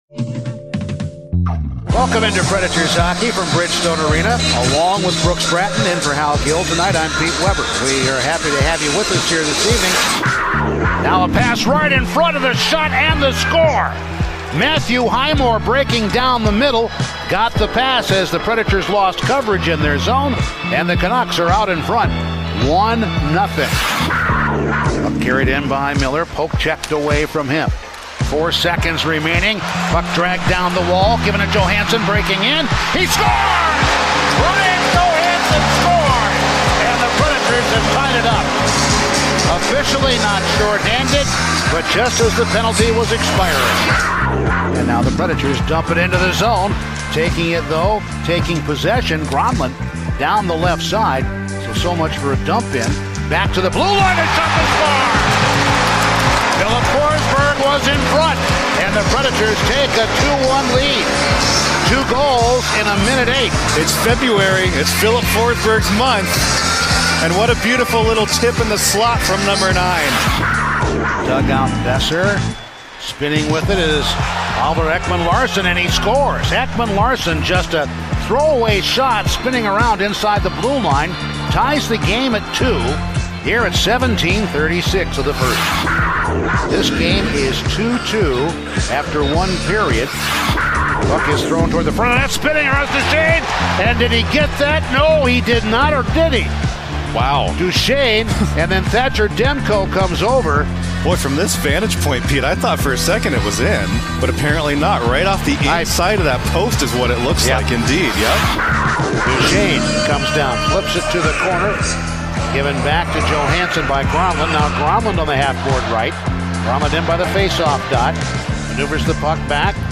Full radio highlights from the Preds' win over the Canucks